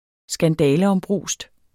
Udtale [ -ʌmˌbʁuˀsd ]